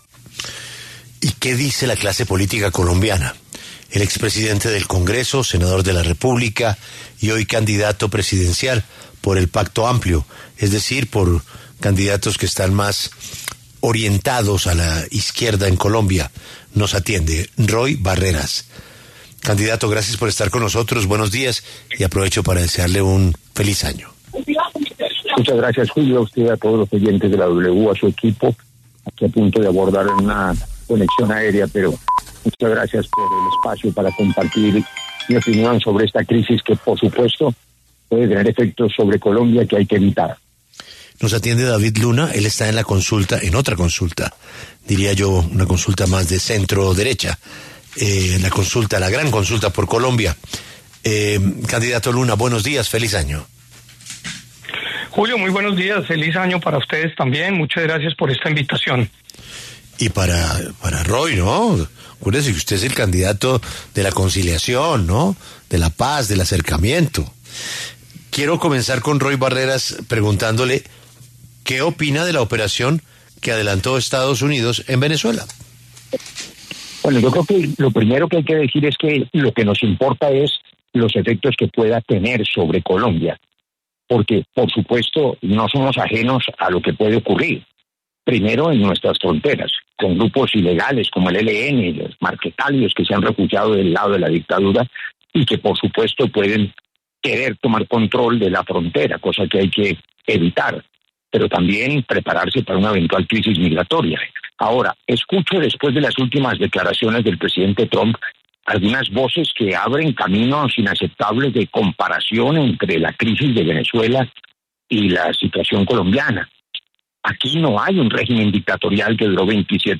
Los exsenadores Roy Barreras y David Luna, precandidatos a la Presidencia, hablaron en La W sobre la captura de Nicolás Maduro y los impactos que podría traer la operación de Estados Unidos en Venezuela.